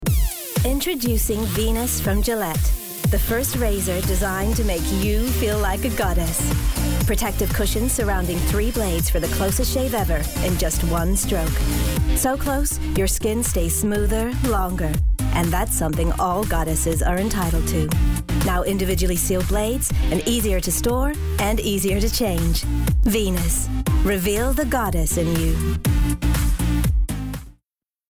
Adult
standard us | character